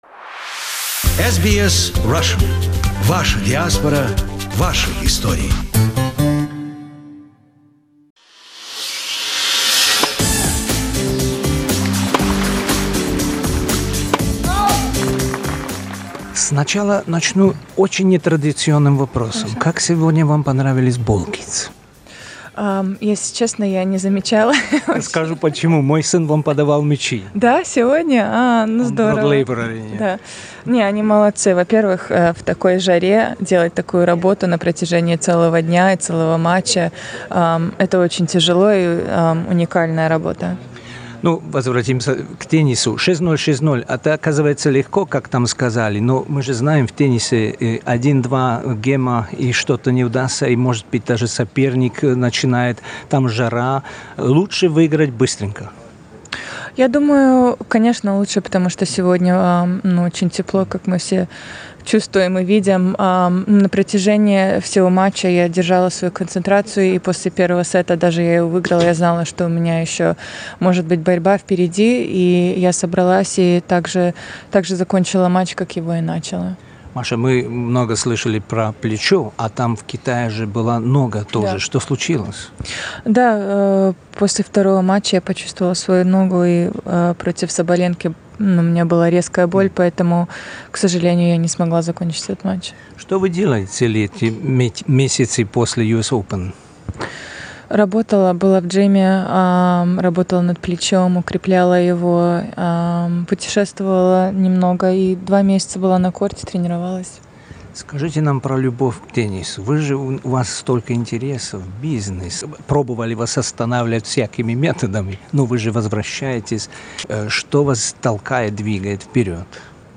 В нашем интервью мы говорим, про ее целеустремленность, любовь к теннису и о постоянном движении вперед.